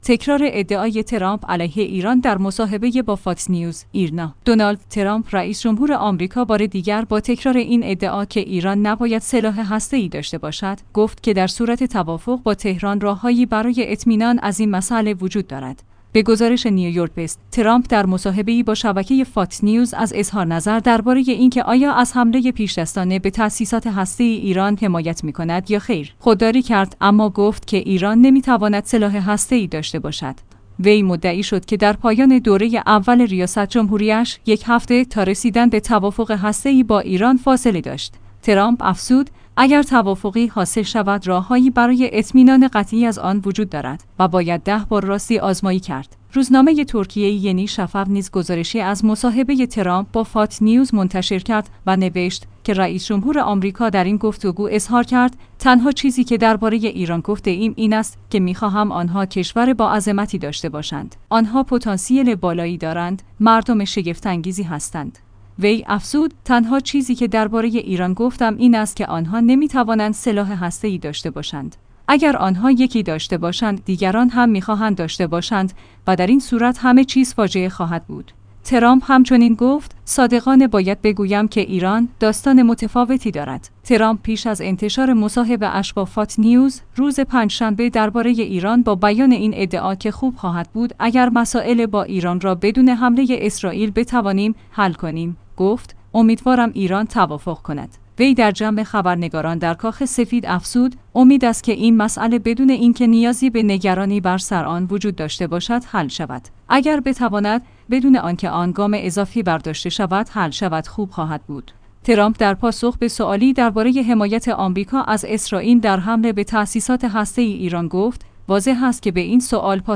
تکرار ادعای ترامپ علیه ایران در مصاحبه با فاکس‌نیوز